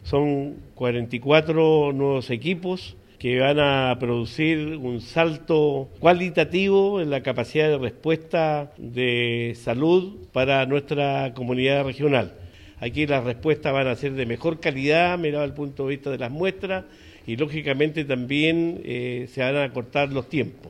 El gobernador regional, Luis Cuvertino, precisó que son 44 nuevos equipos que se destinarán al Laboratorio de la Seremi de Salud, indicando que con ello se mejorará la capacidad de análisis y se acortarán los tiempos de respuesta.